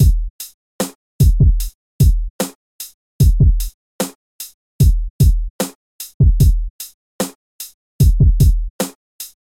乐活大鼓
描述：缓慢的嘻哈鼓
Tag: 75 bpm Hip Hop Loops Drum Loops 1.62 MB wav Key : Unknown